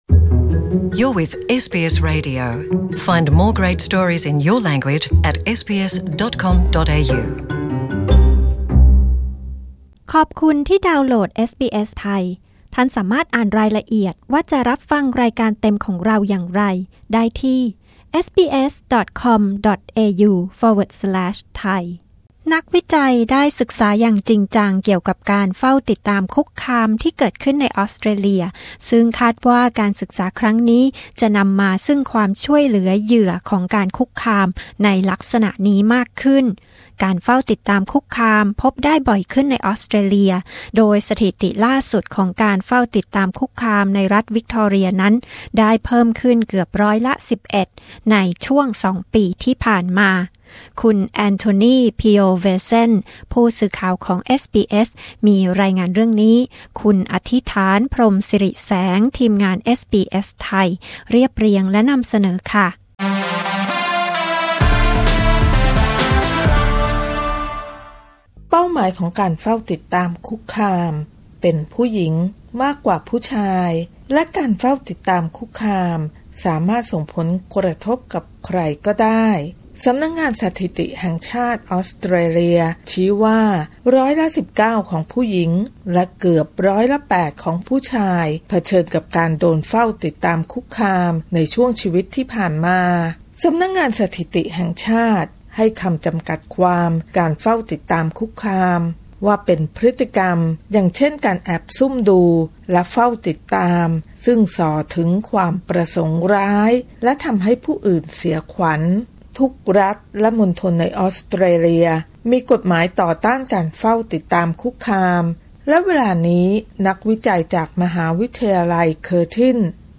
กดปุ่ม 🔊 ด้านบนเพื่อฟังสัมภาษณ์เรื่องนี้